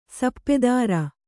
♪ sappe dāra